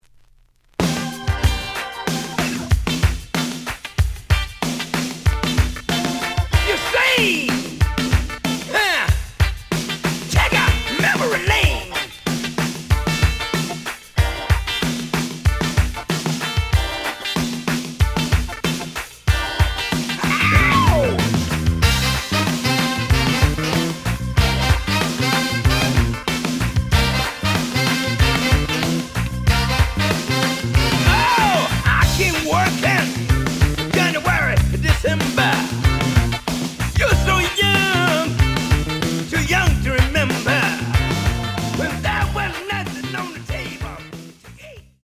試聴は実際のレコードから録音しています。
●Format: 7 inch
●Genre: Funk, 80's / 90's Funk